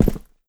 jumpland1.wav